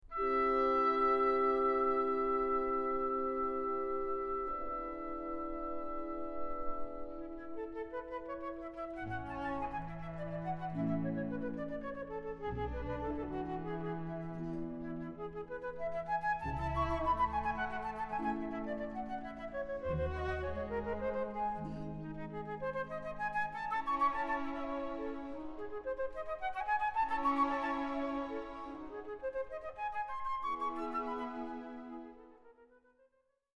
Ballet Music